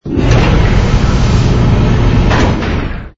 door_small_close.wav